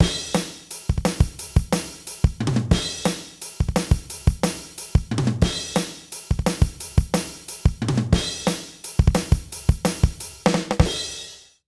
British ROCK Loop 177BPM.wav